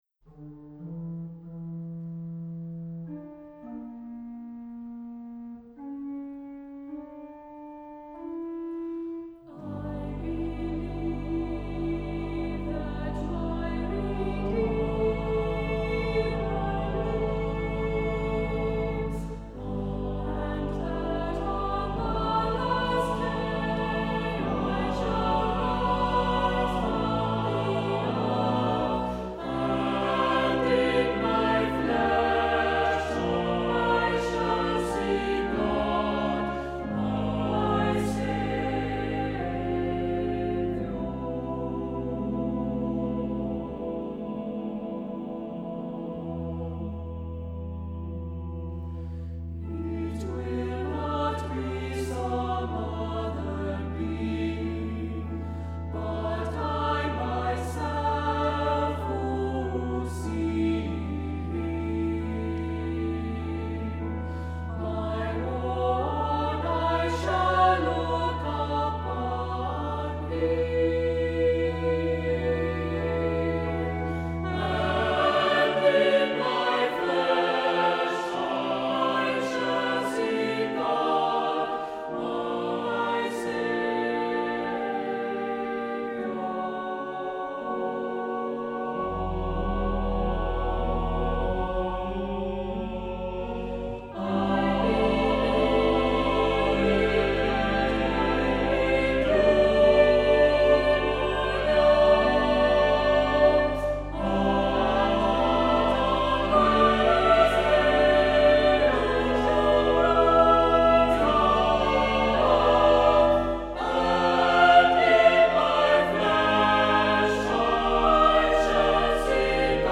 Accompaniment:      Organ, Soprano Solo
Music Category:      Christian
Soprano solo is optional